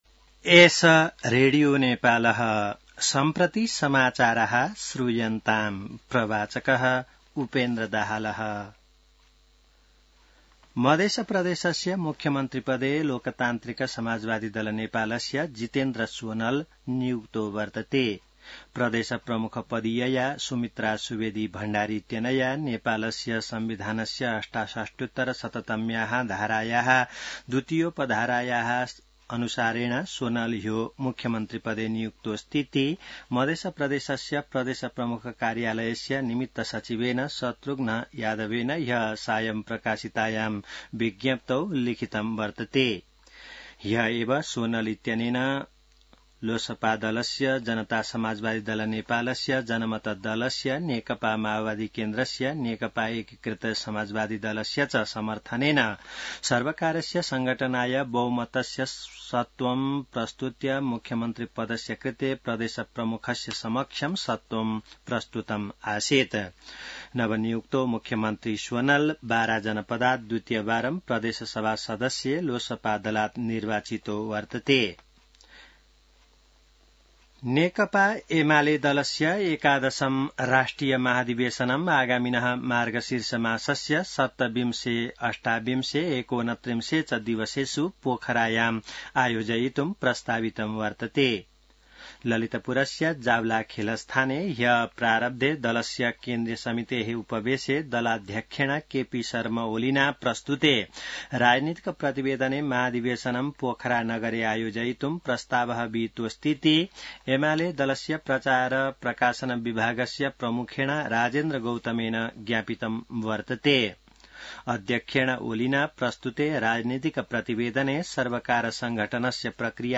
An online outlet of Nepal's national radio broadcaster
संस्कृत समाचार : ३० असोज , २०८२